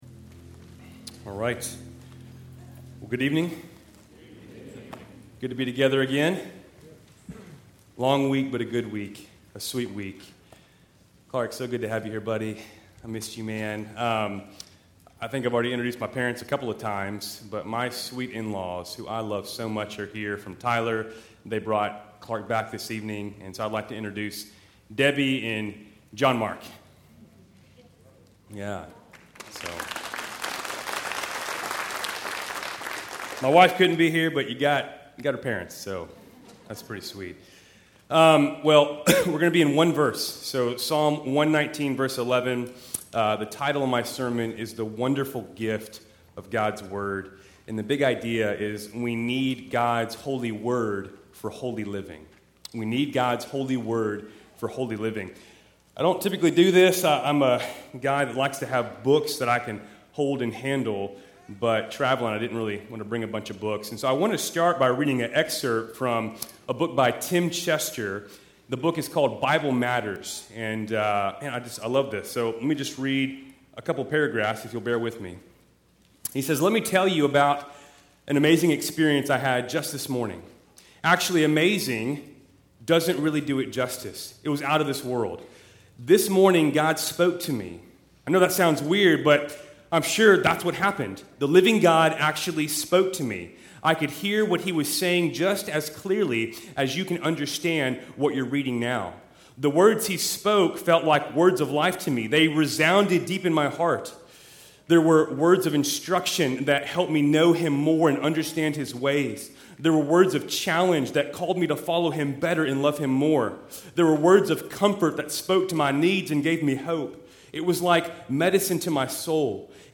Keltys Worship Service, June 23, 2024